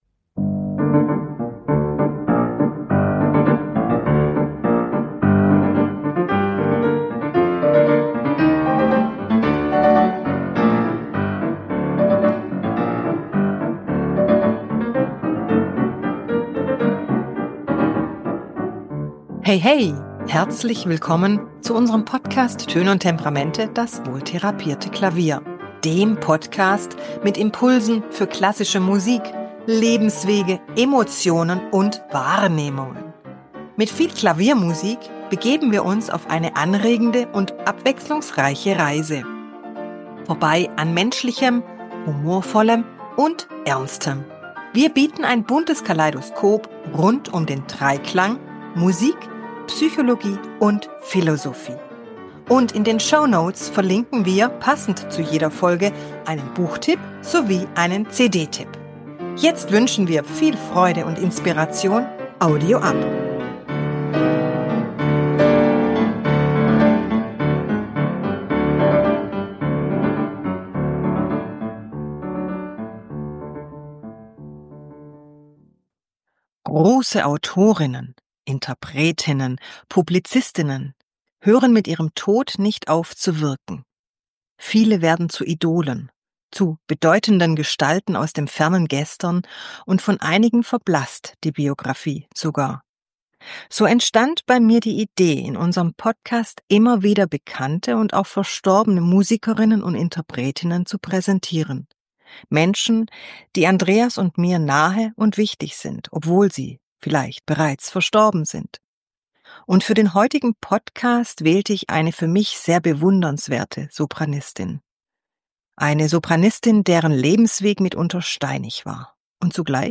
Als Musik wählte ich für diesen Podcast Robert Schumanns Blumenstück (Des-Dur, Op. 19), weil Maria Callas auf mich wie eine zarte und auch fragile Blume wirkt die gleichzeitig vielen Wettergegebenheit trotzen konnte und ihre farbige Pracht künstlerisch grandios zeigte.
Das Blumenstück wirkt graziös und die Komposition scheint Schumanns reiner Spielfreude entsprungen zu sein.